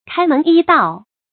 kāi mén yī dào
开门揖盗发音
成语正音 揖，不能读作“jí”或“jì”。